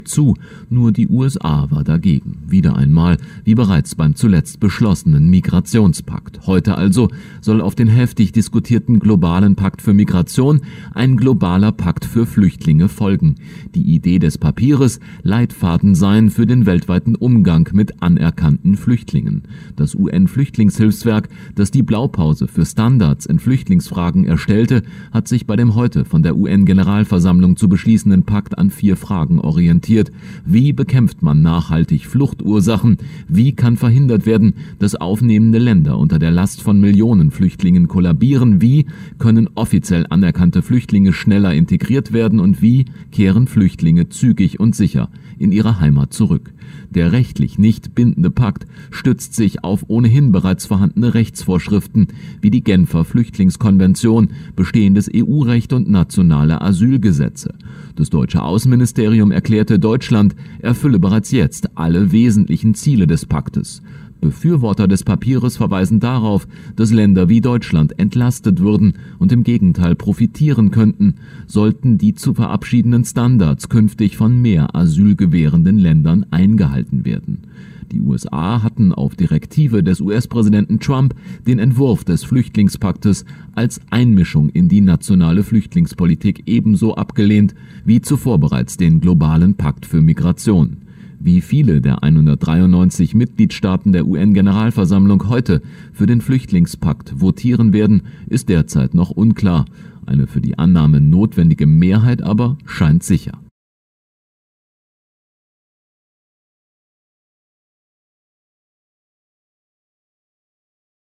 … ein Interview des Dlf mit dem Rechtswissenschaftler Reinhard Merkel: